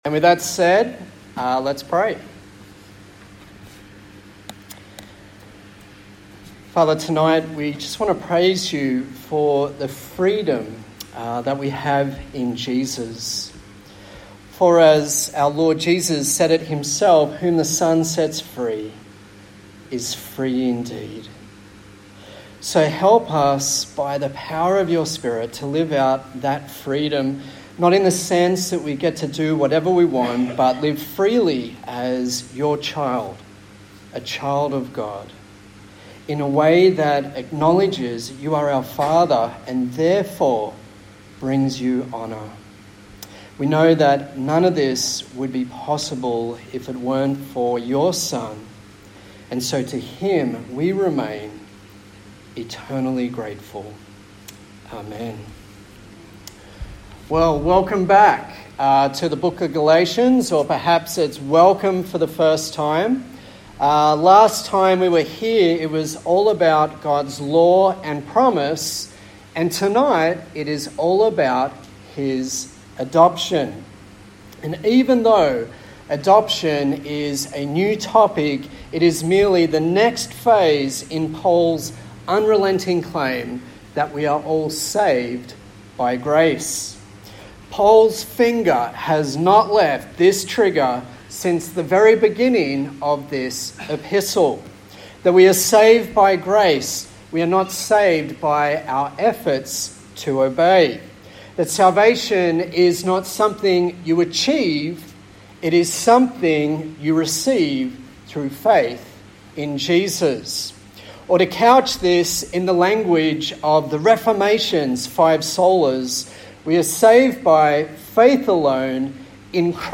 A sermon in the series on the book of Galatians